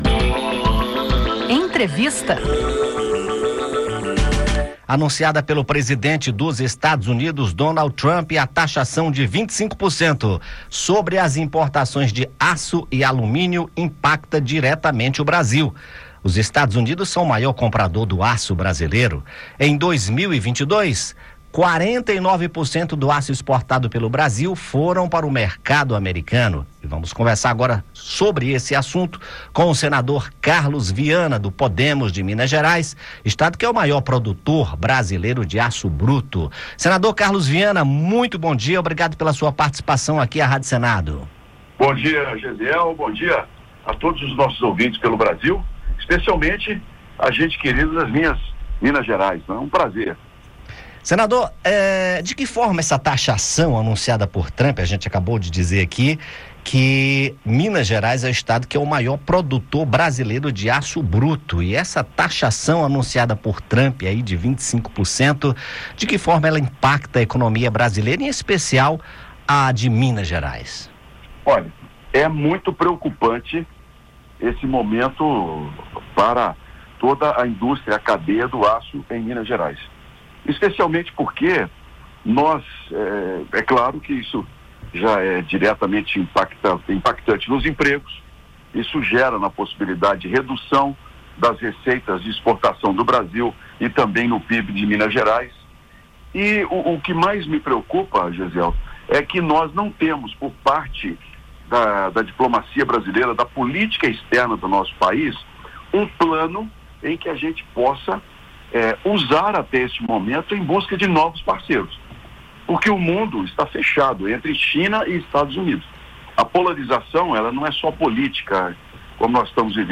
Em entrevista à Rádio Senado, o senador Carlos Viana (Podemos-MG) destaca o impacto da medida, em especial no estado de Minas Gerais, que é o maior produtor brasileiro de aço bruto. O senador comenta o tom cauteloso do governo brasileiro, que evita falar em retaliação, e as expectativas de o Brasil direcionar a exportação de aço e alumínio para outros mercados.